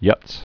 (yŭts)